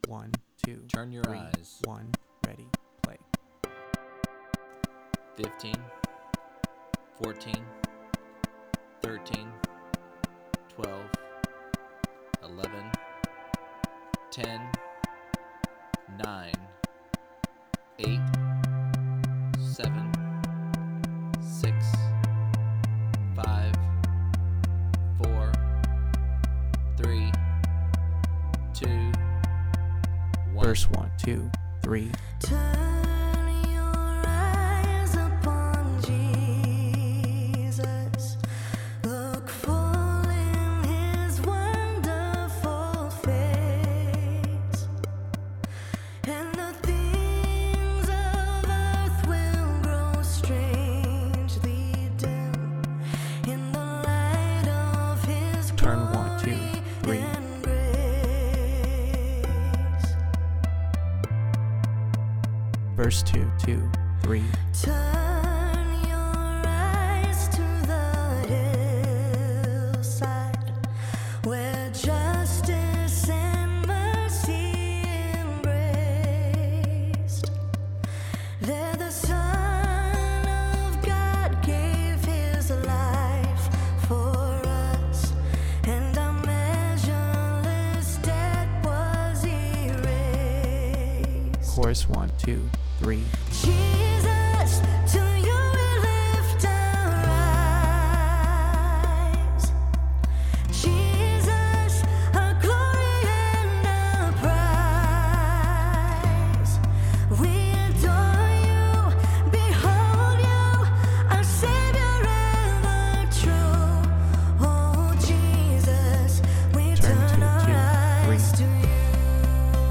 turn_your_eyes_lifeway_c_add_gtr_bass.m4a